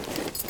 tac_gear_18.ogg